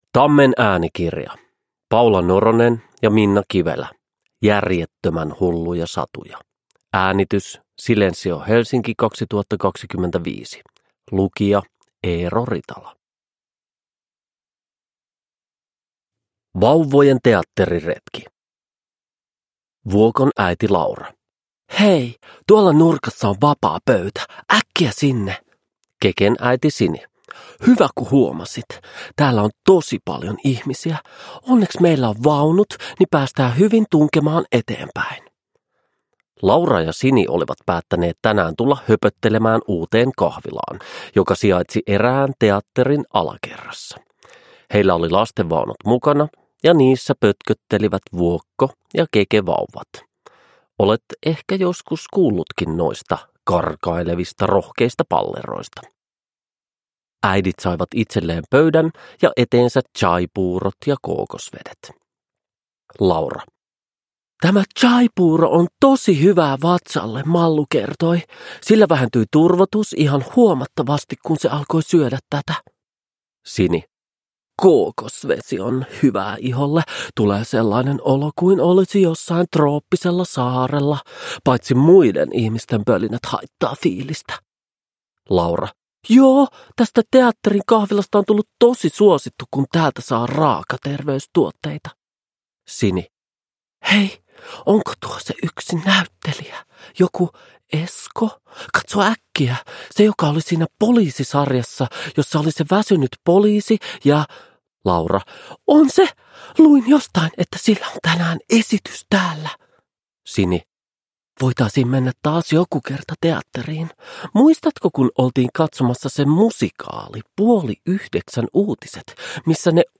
Järjettömän hulluja satuja – Ljudbok